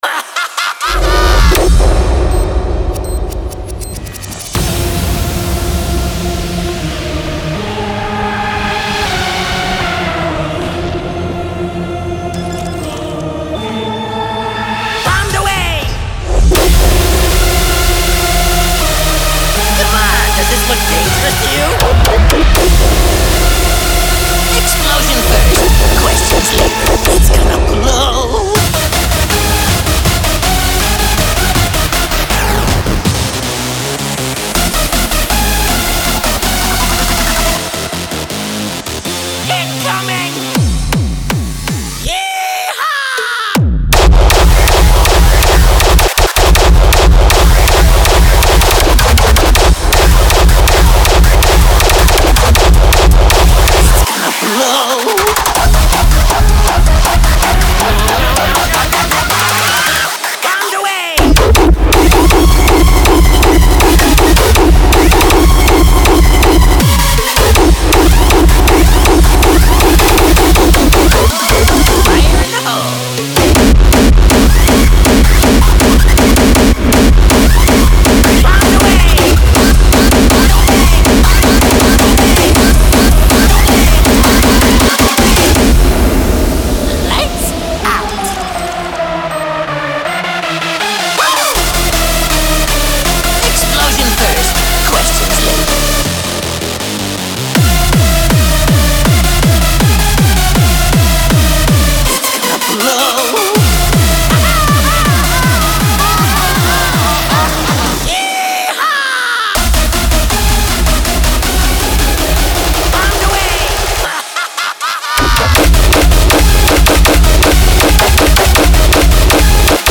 • Жанр: Hardstyle